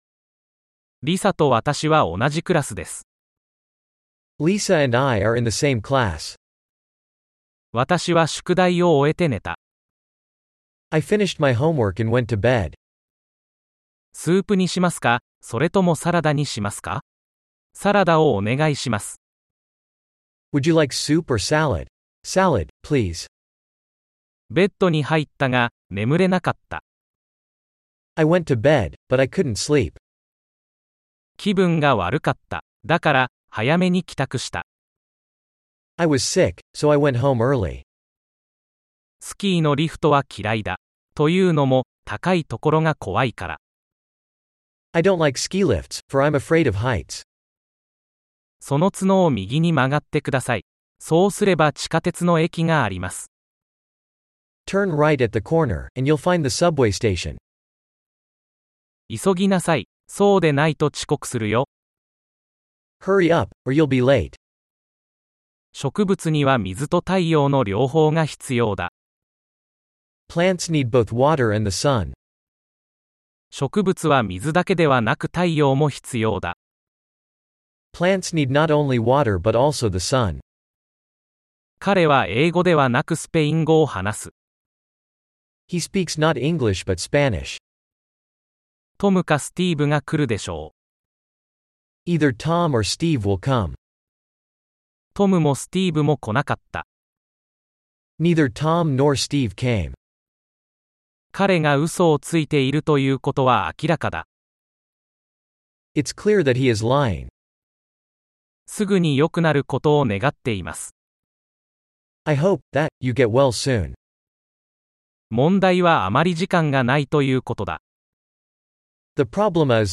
高校でよく使われている 『Vision Quest Ultimate』の日本語→英語音声を作りました(音声ファイルはここをクリック・タップ) 。